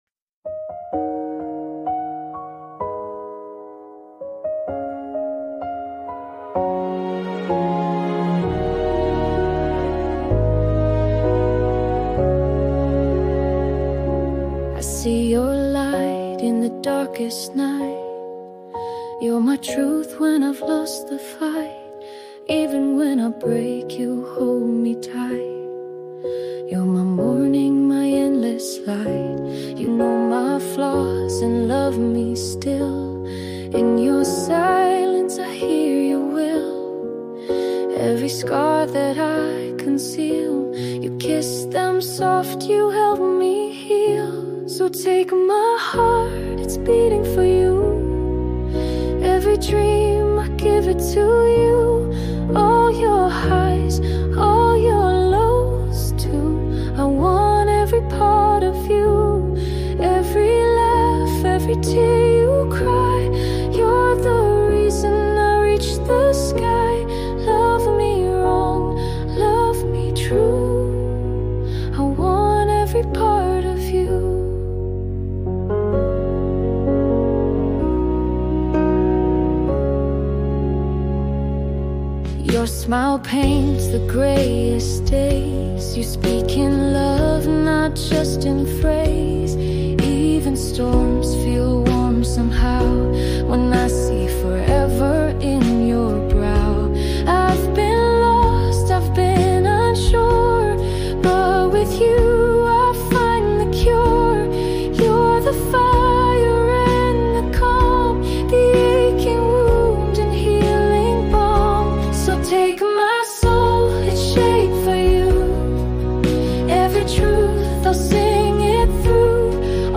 Romantic Love Song | Heartfelt Female Vocal Ballad